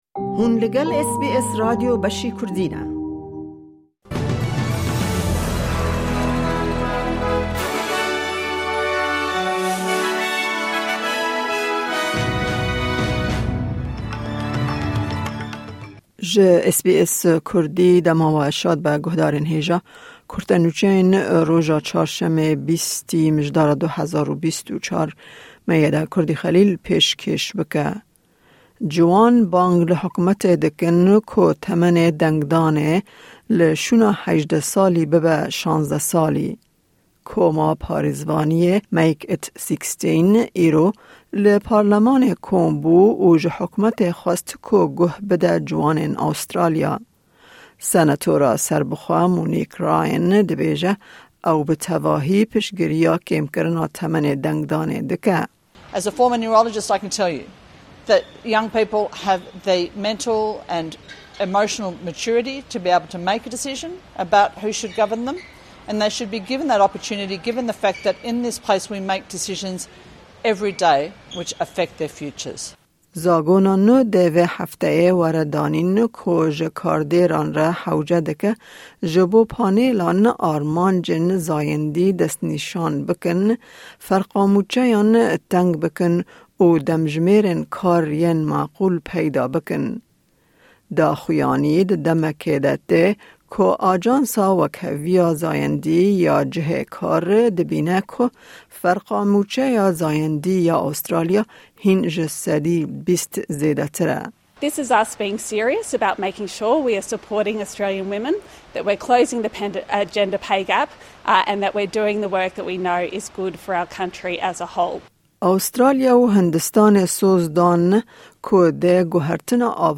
Kurte Nûçeyên roja Çarşemê 20î Mijdara 2024